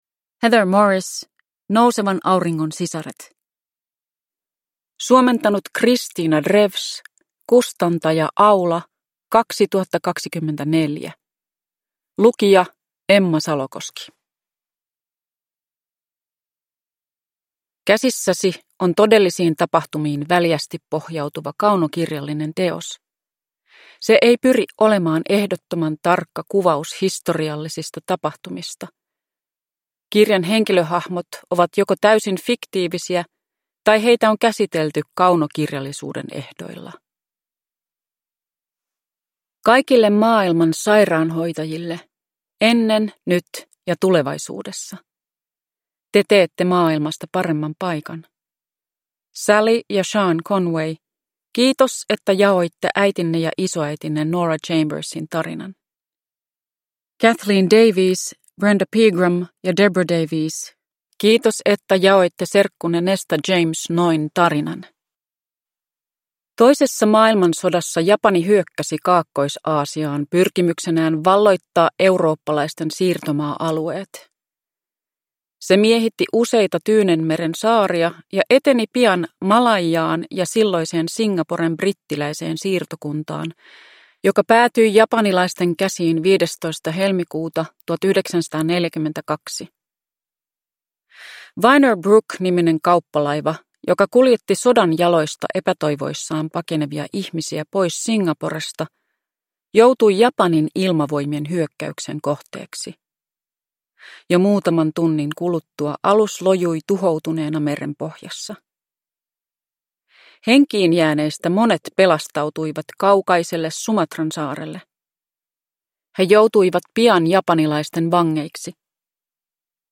Uppläsare: Emma Salokoski
Ljudbok